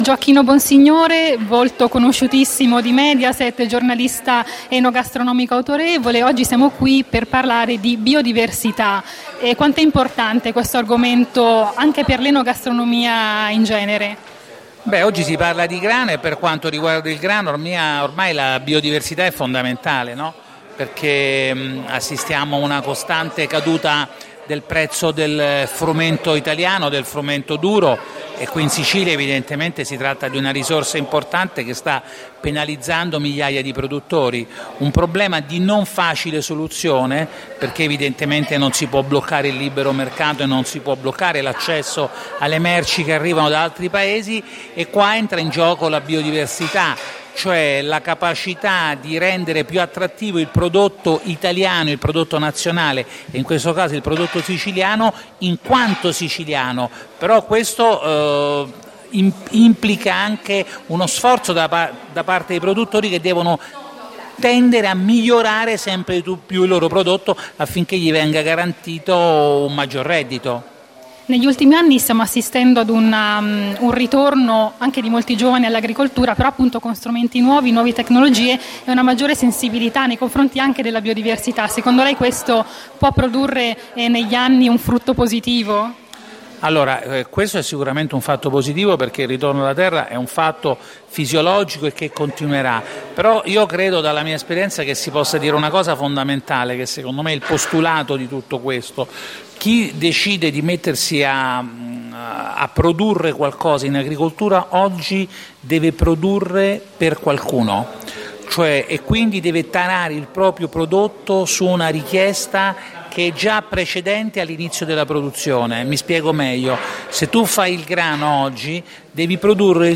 L’unità d’Italia passa attraverso il gusto. Intervista a Gioacchino Bonsignore
Nel corso del convegno dedicato ai grani antichi siciliani e promosso dall’Etna Food Academy, abbiamo rivolto alcune domande a Gioacchino Bonsignore, volto Mediaset e autorevole giornalista enogastronomico.